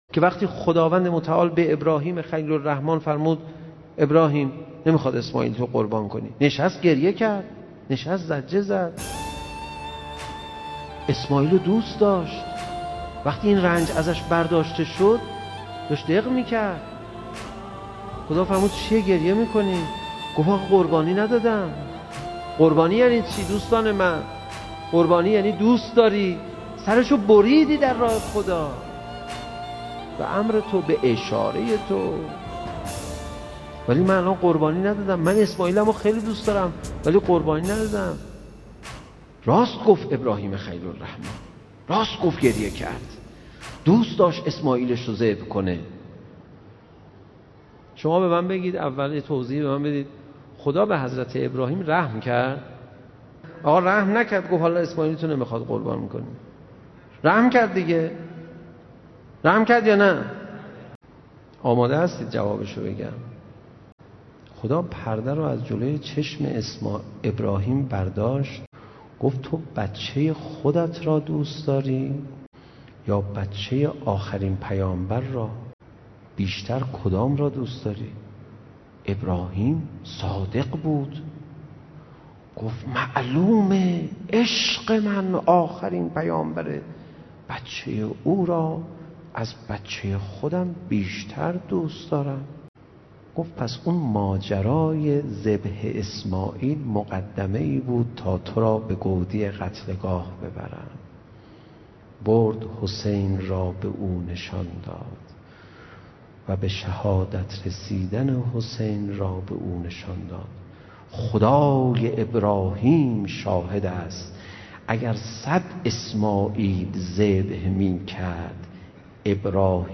کلیپ صوتی از سخنرانی استاد پناهیان